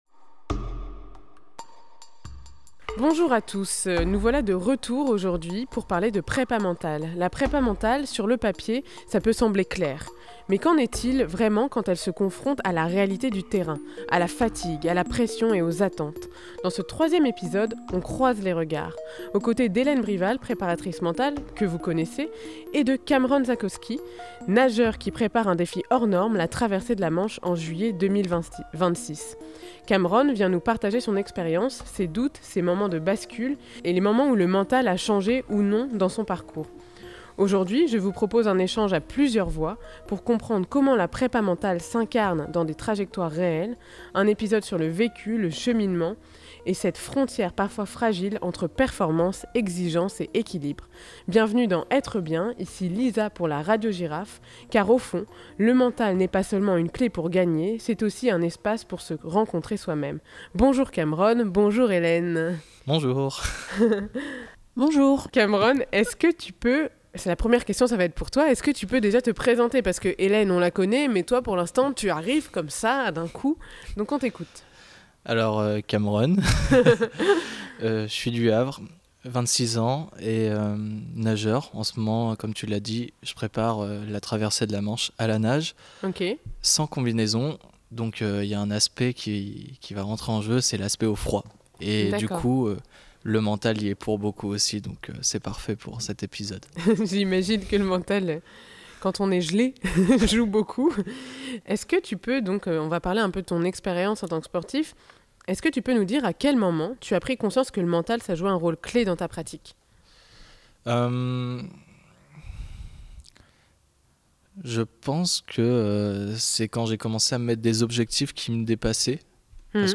Un échange à plusieurs voix pour comprendre comment la préparation mentale s’incarne dans des trajectoires réelles, loin des discours idéalisés.